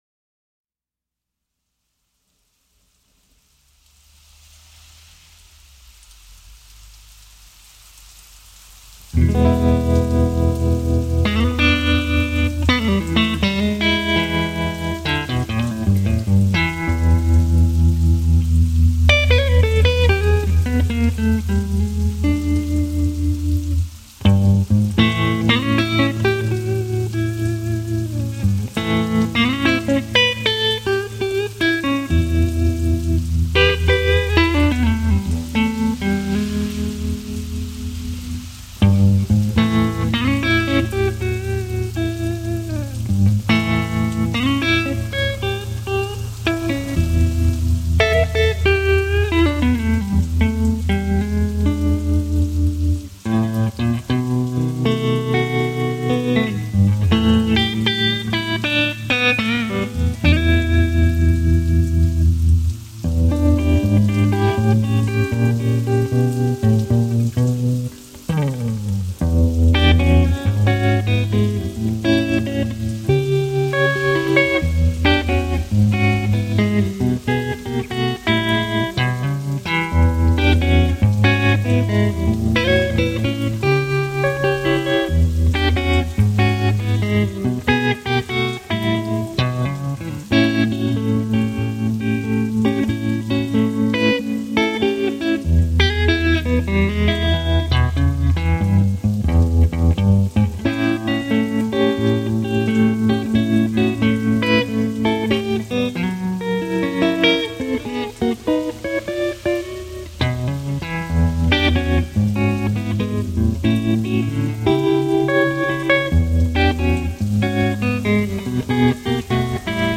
Blues guitar legend in the making.